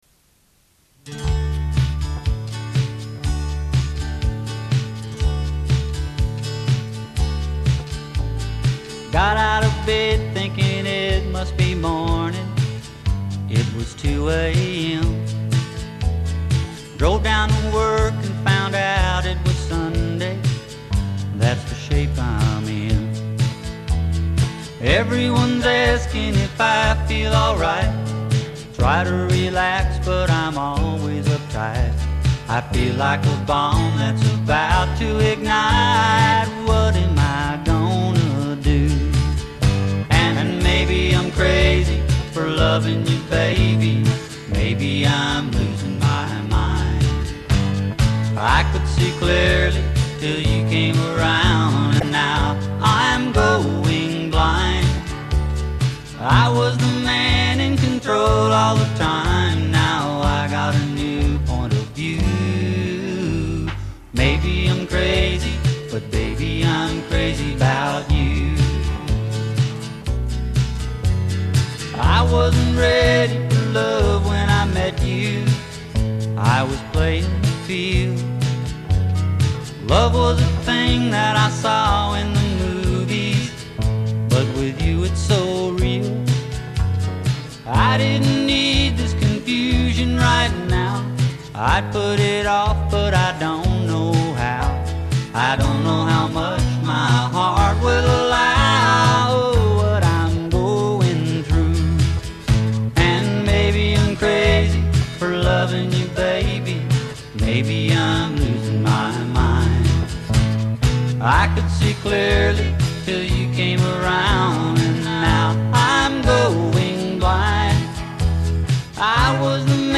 4 TRACK DEMO
CASLIN BROTHERS